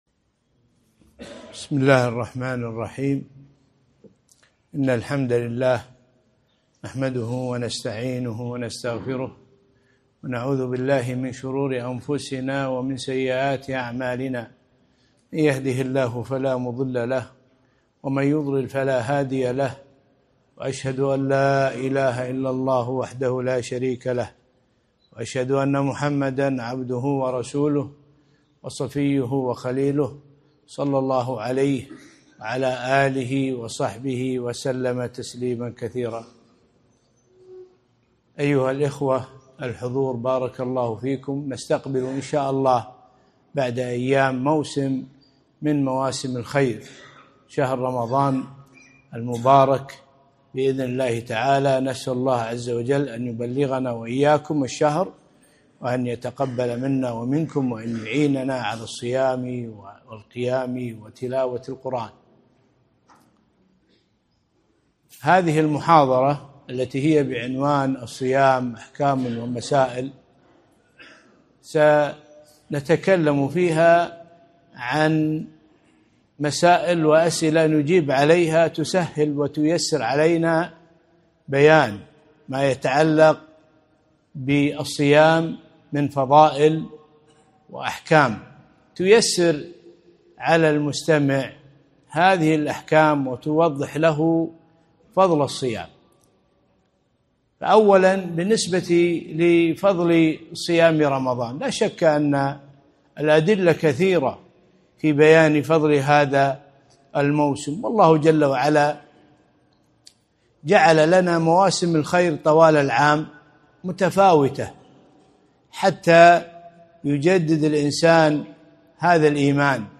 محاضرة - الصيام أحكام ومسائل